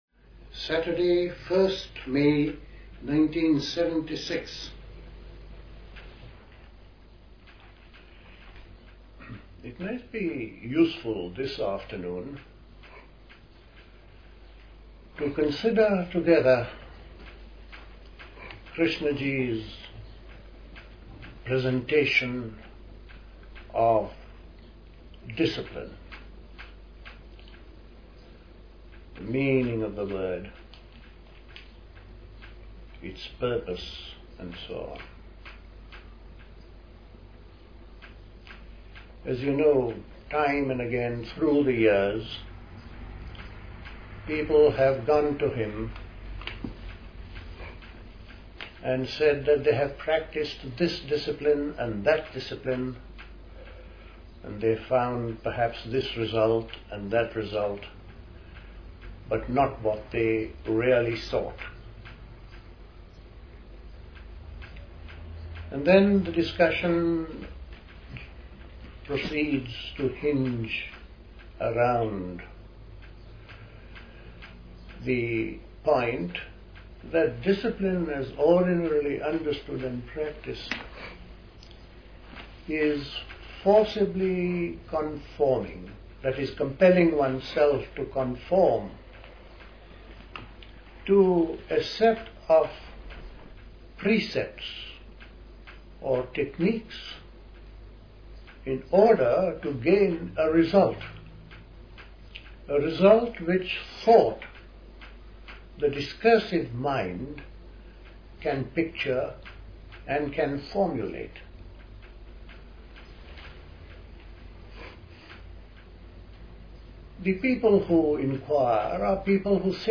Recorded at a Krishnamurti meeting.